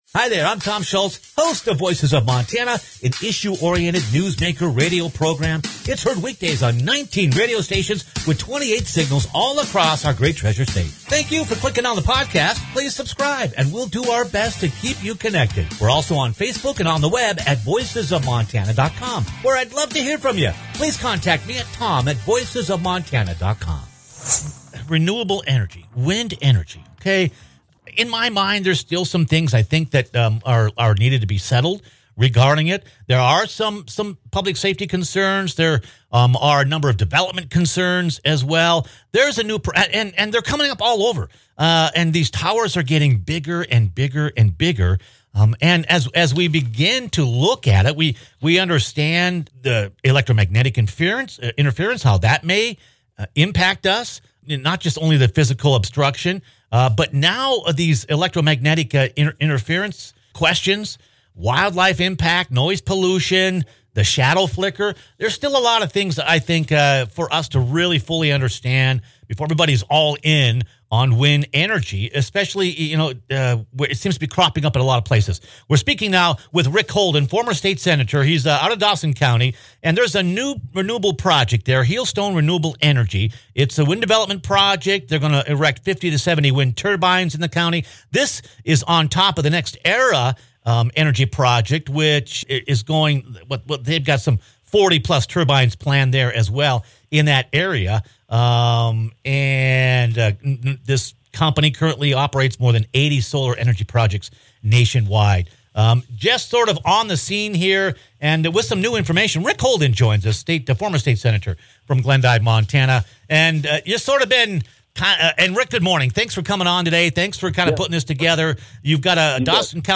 Plans are in the making for another large wind energy venture proposed for eastern Montana. Former State Senator Ric Holden, from Glendive, joins the program to size up the project and the concerns being voiced by area residents.